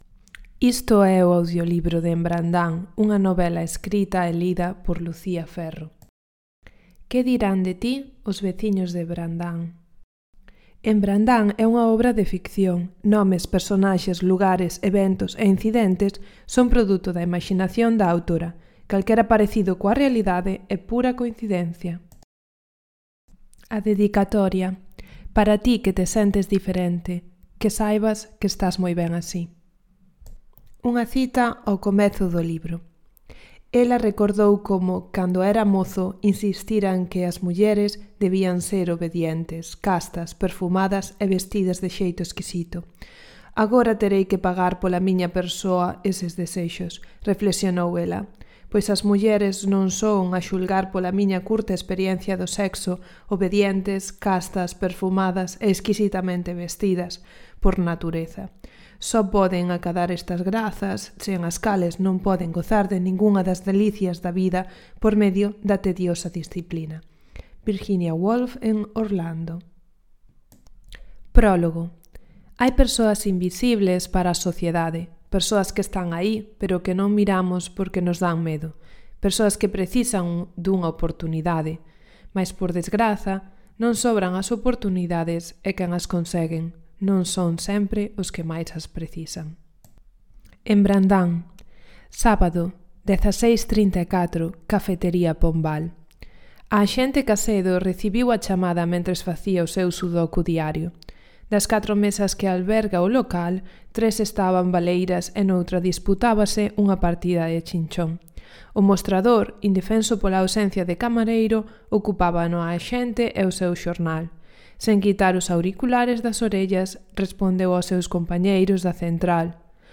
mostra_audiolibro_brandan.mp3